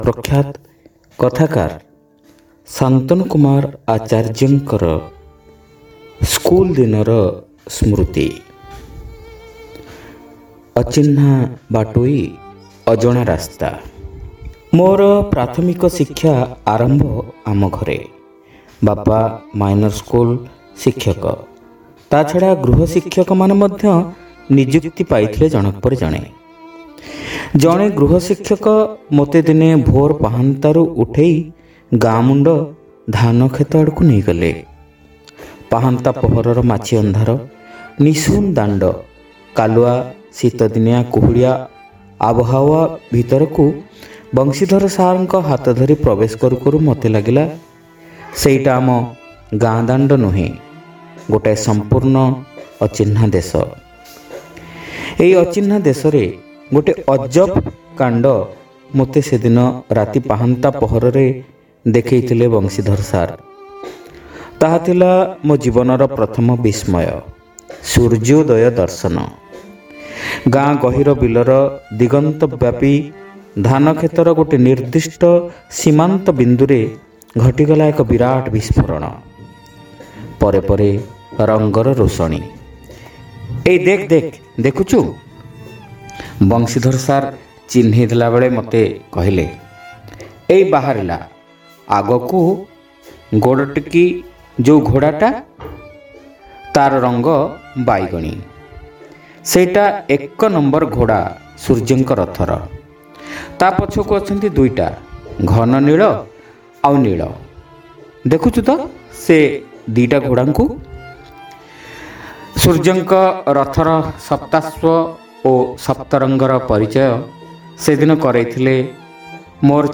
ଶ୍ରାବ୍ୟ ଗଳ୍ପ : ଅଚିହ୍ନା ବାଟୋଇ ଅଜଣା ରାସ୍ତା